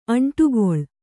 ♪ aṇṭugoḷ